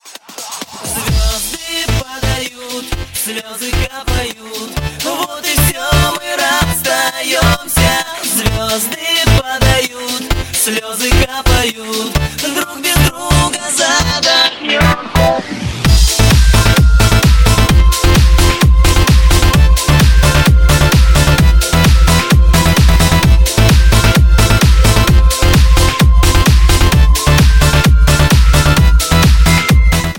• Качество: 128, Stereo
мужской голос
мощные
Electronic
Club House
Занятный ремикс знаменитого трека